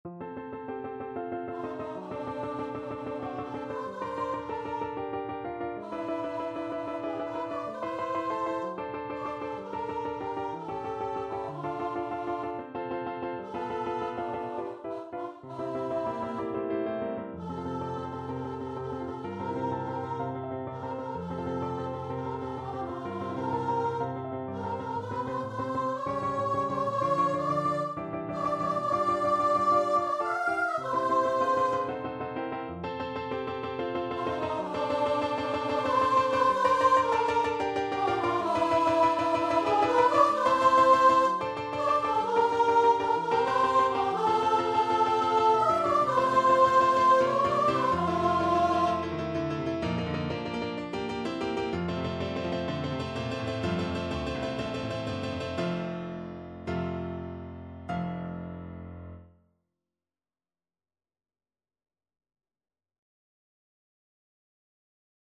~ = 63 Ziemlich rasch, leidenschaftlich
Classical (View more Classical Voice Music)